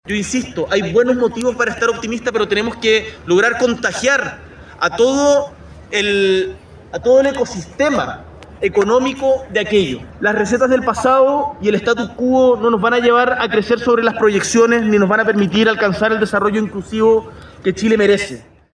Se trata del EtMday, evento de innovación donde el presidente Boric también tuvo unas palabras de bienvenida.